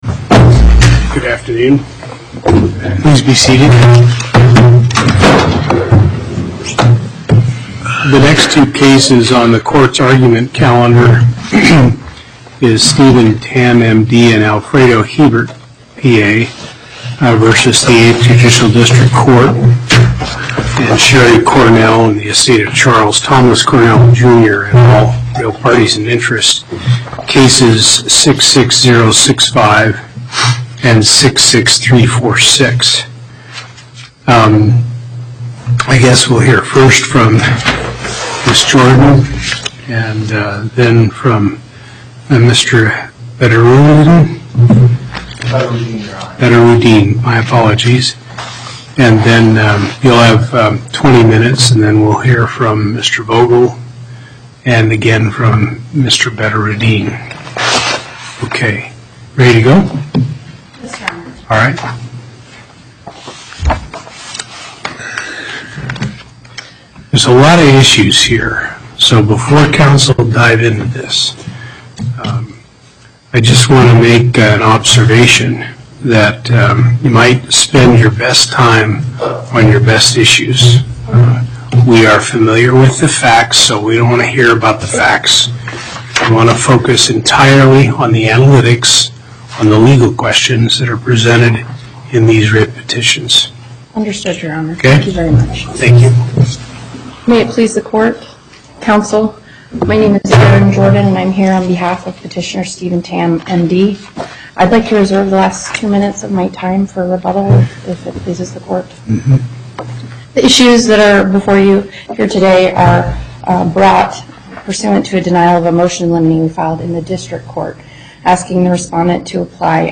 Location: Las Vegas Before the En Banc Court, Chief Justice Hardesty Presiding
as counsel for Petitioner
as counsel for Real Parties in Interest